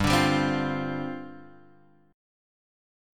G7sus4 chord